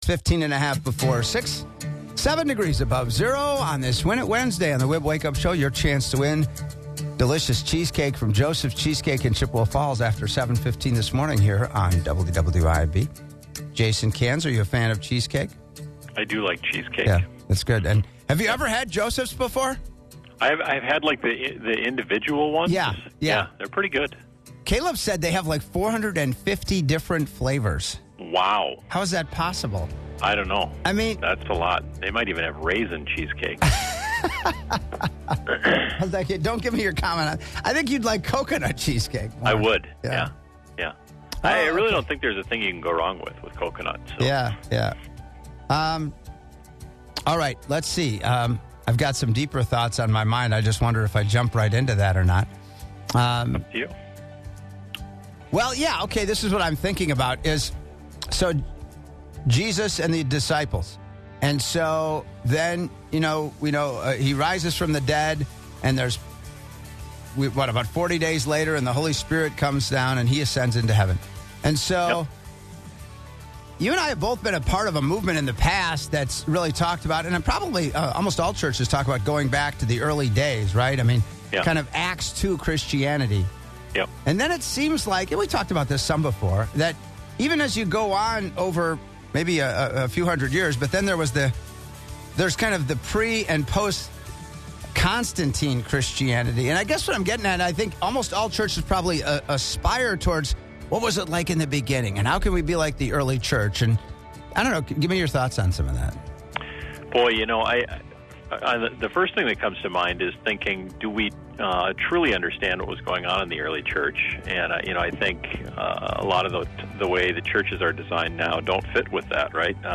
with the call from Hobbs Ice Center